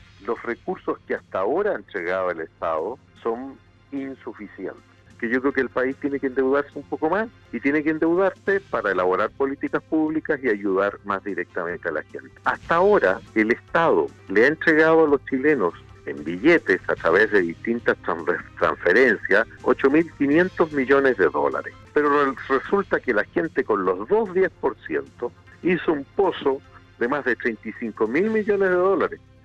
En entrevista con Radio Sago, el Senador por la región de Los Lagos, Iván Moreira, se refirió a un posible nuevo retiro del 10 por ciento desde el fondo de pensiones.